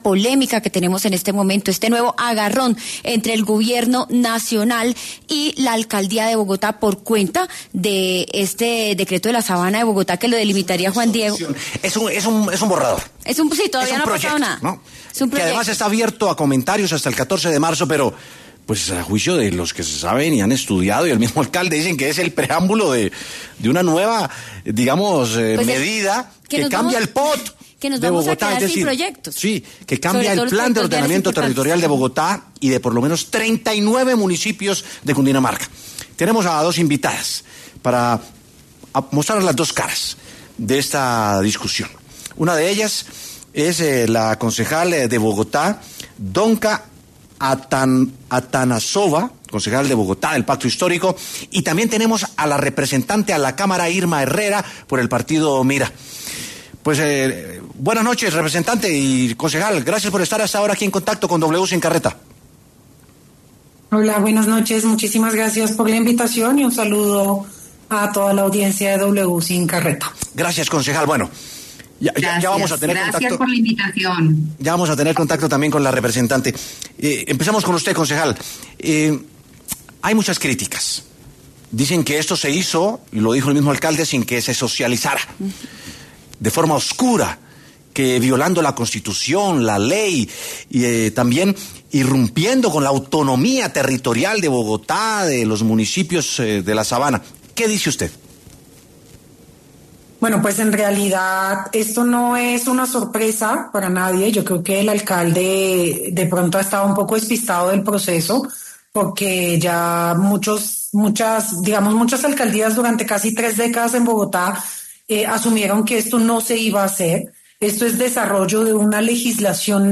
Debate: ¿cuáles son las afectaciones de la resolución del MinAmbiente sobre la Sabana de Bogotá?
La concejal de Bogotá Donka Atanassova y la representante a la Cámara Irma Herrera analizaron la nueva controversia entre el Alcaldía de Bogotá y Gobierno Petro.